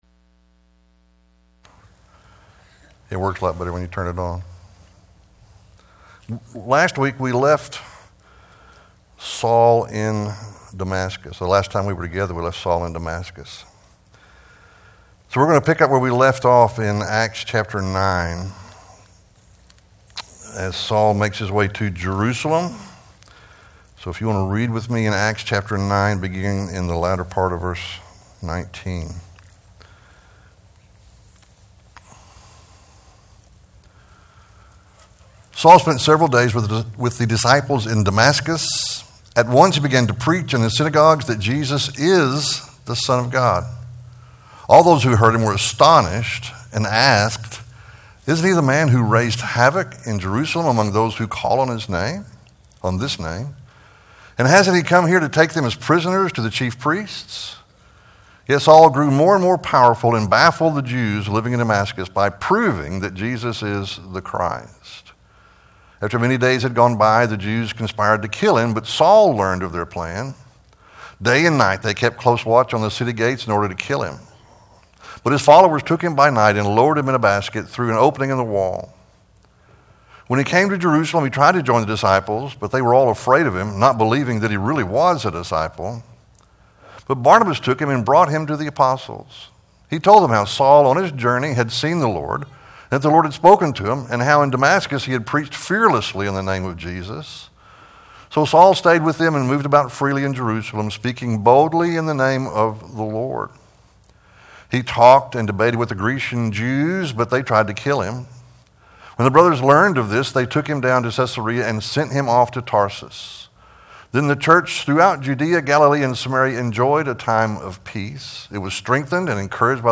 Aeneas & Dorcas Preacher